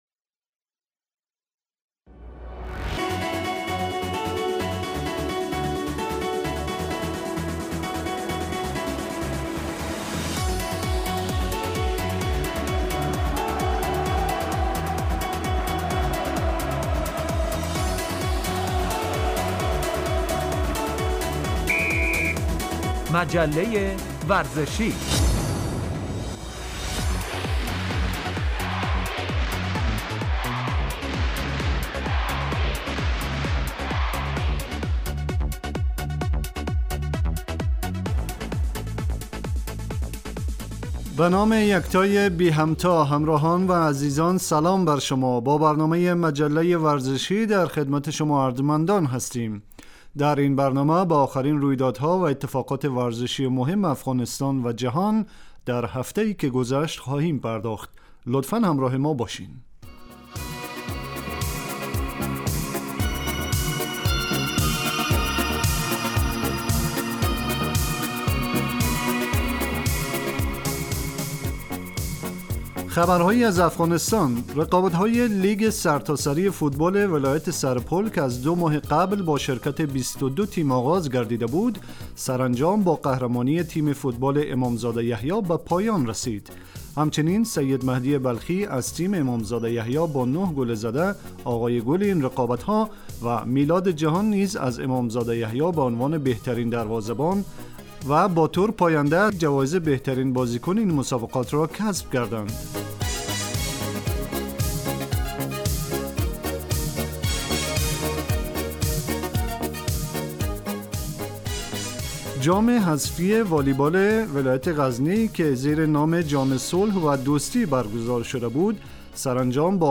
آخرین رودیدادها و اتفاقات ورزشیِ مهم افغانستان و جهان در هفته ای که گذشت به همراه مصاحبه و گزارش و بخش ورزش و سلامت